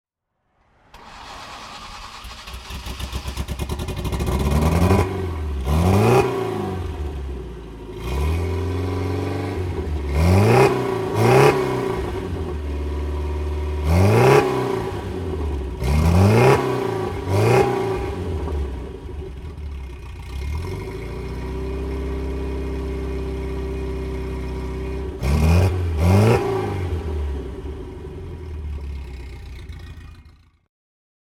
Fiat Barchetta 103 Sport 1200 S (1957) - Starten und Leerlauf